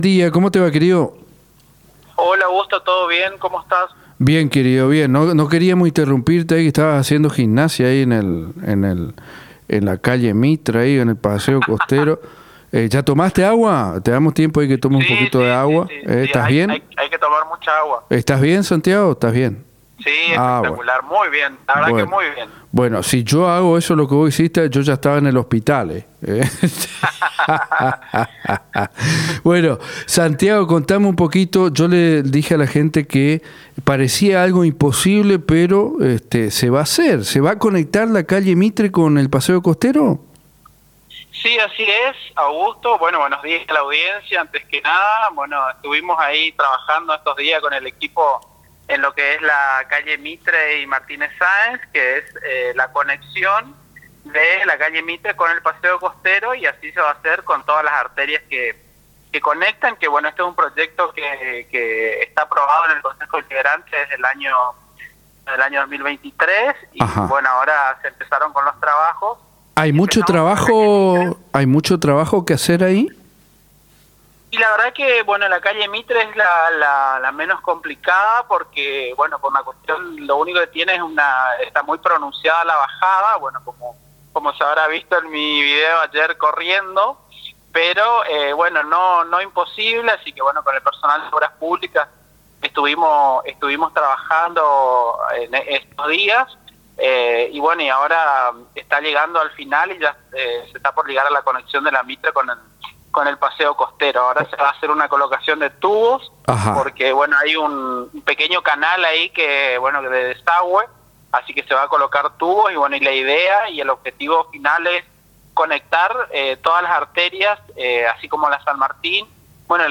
ENTREVISTA CONCEJAL SAUCEDO